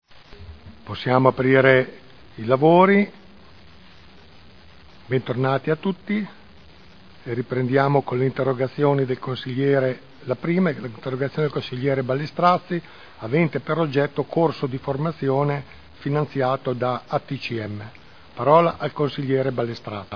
Seduta del 09/01/2012. Il Presidente Pellacani apre i lavori della seduta.